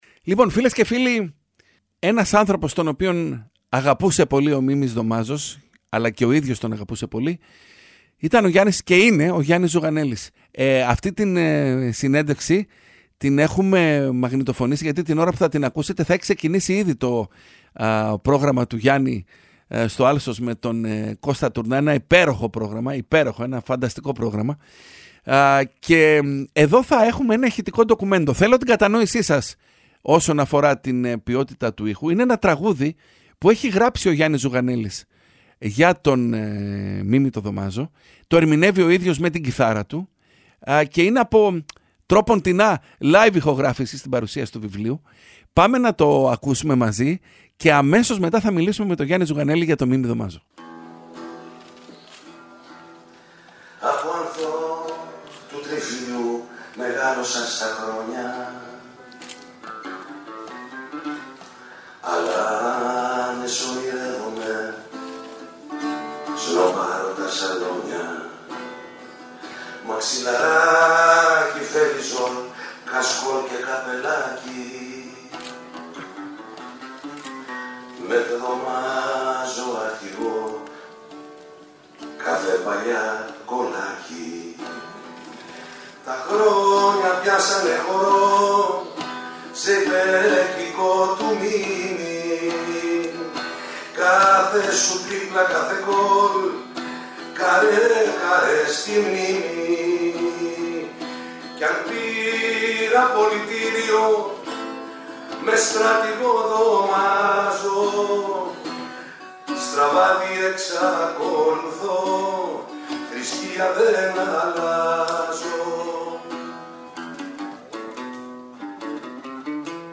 Γιάννης Ζουγανέλης σε Real fm 97,8: «Εξαιτίας του Μίμη Δομάζου θαύμαζα τους Έλληνες ποδοσφαιριστές!»